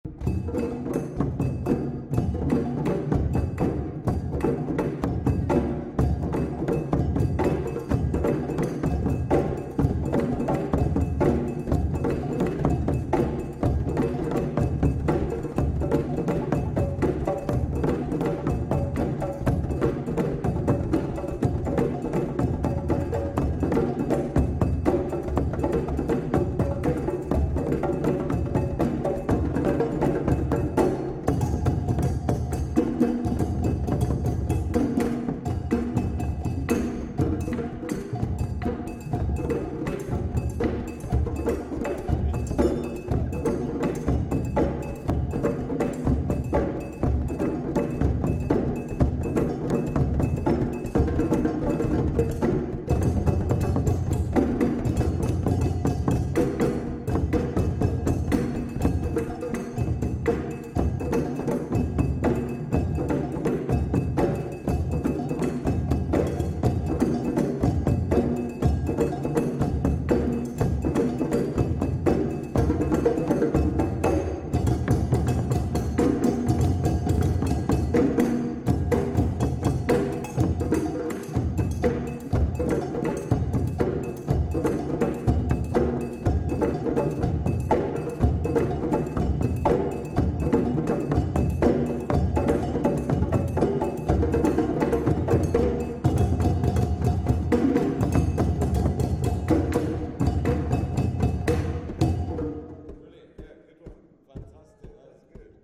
DrumLove Same Energetic Beat, but Faster